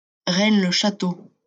Rennes-le-Château (French pronunciation: [ʁɛn ʃato]